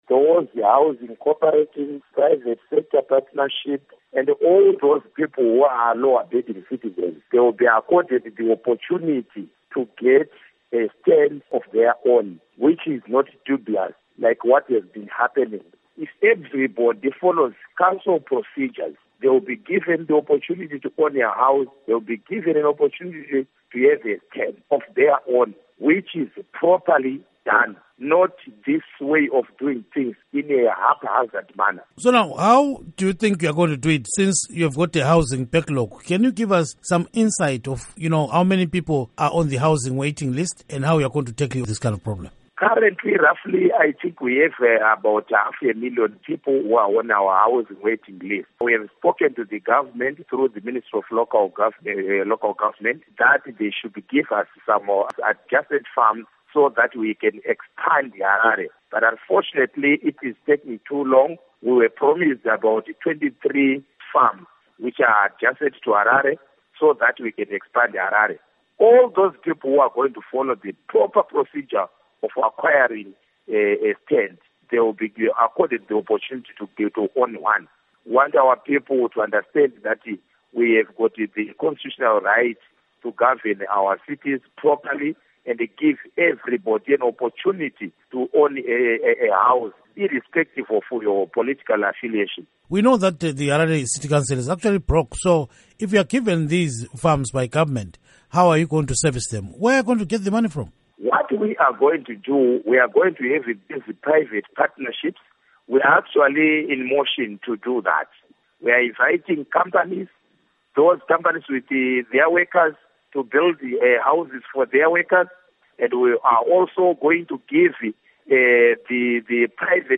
Interview With Councillor Moyo